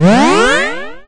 Heal5.ogg